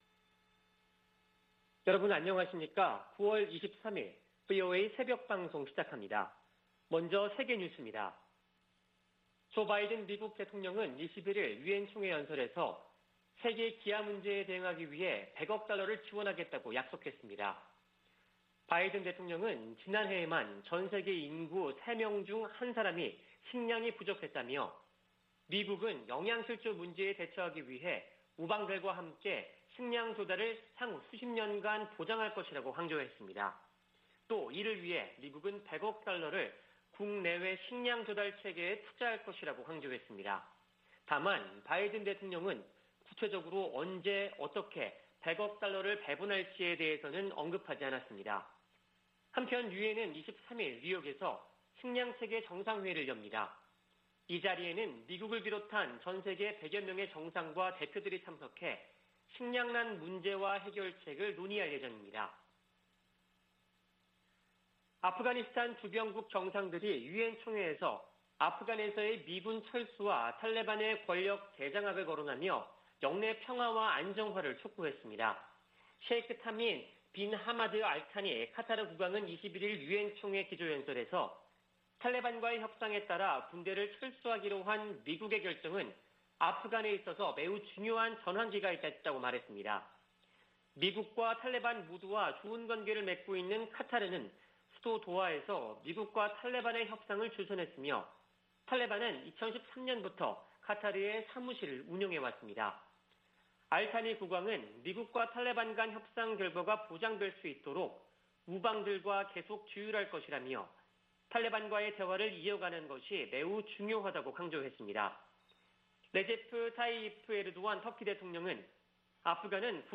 VOA 한국어 '출발 뉴스 쇼', 2021년 9월 23일 방송입니다. 조 바이든 미국 대통령이 한반도 완전 비핵화를 위해 지속적 외교와 구체적 진전을 추구한다고 밝혔습니다. 문재인 한국 대통령은 종전선언을 제안했습니다. 미 연방수사국(FBI)이 북한의 사이버 역량 증대를 지적했습니다.